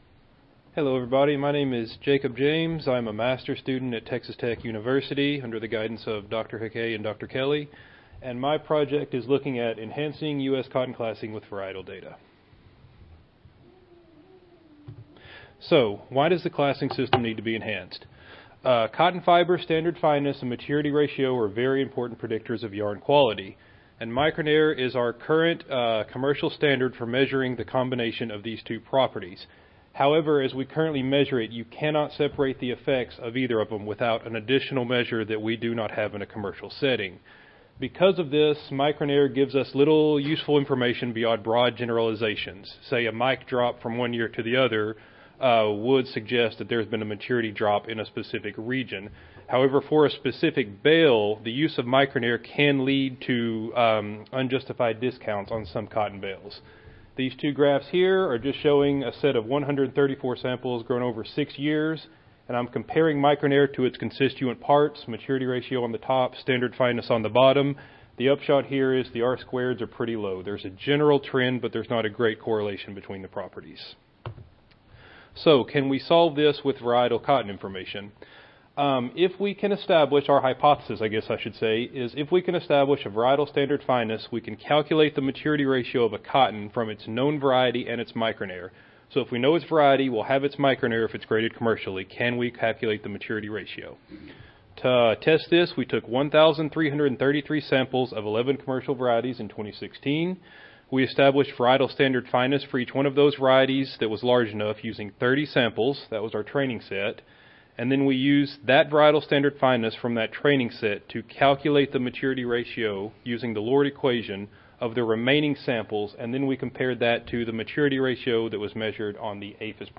Cotton Improvement - Lightning Talks Student Competition
Audio File Recorded Presentation